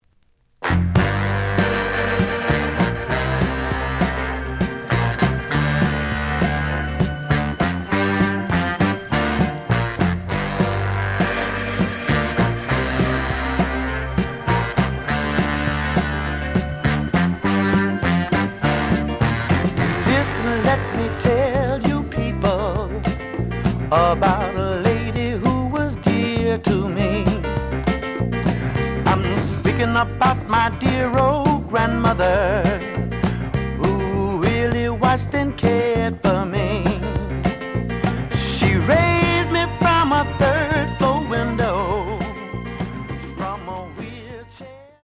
The audio sample is recorded from the actual item.
●Genre: Soul, 70's Soul
Some damage on both side labels. Plays good.)